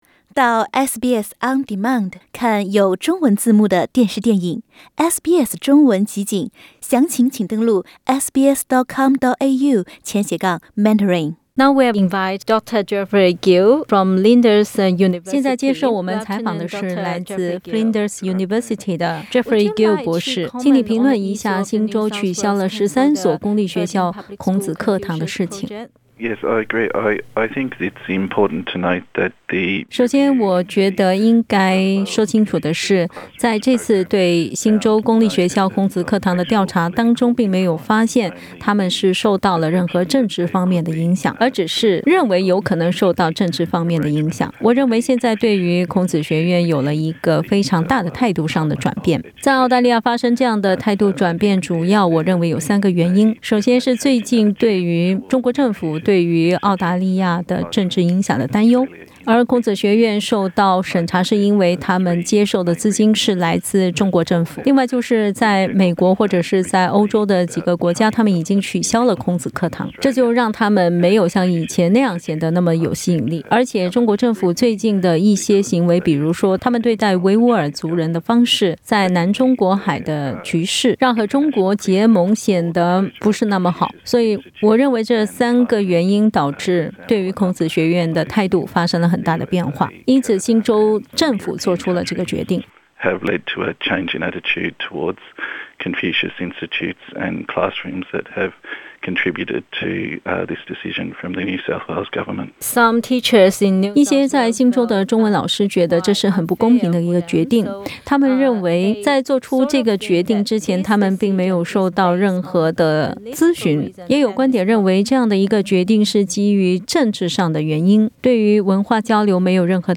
接受SBS普通话采访。